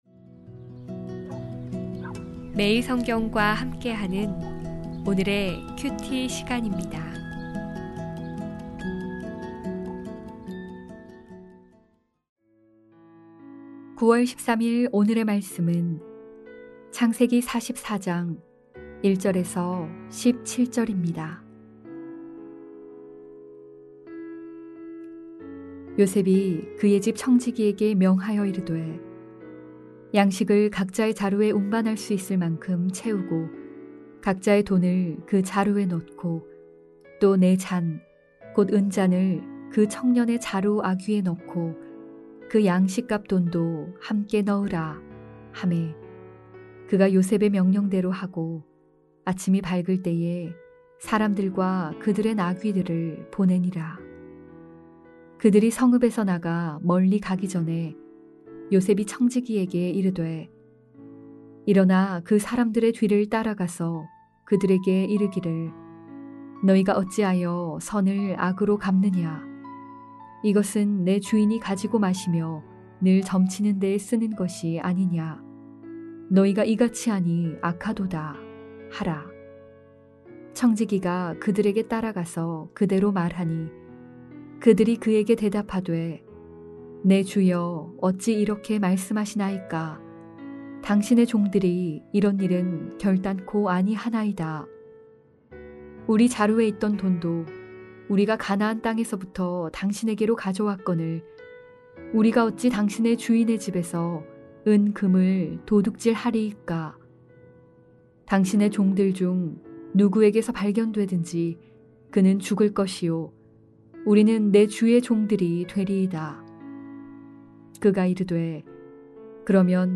해설/말씀 듣기 클릭 09.13.Sun. 요셉의 시험 본문의 중심내용 만찬에 초대하여 형들을 안심시킨 요셉은, 이제 곤경에 빠진 라헬의 다른 아들, 베냐민을 형들이 어떻게 대하는지 그들의 진심을 알아보려 합니다.